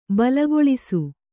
♪ balagoḷisu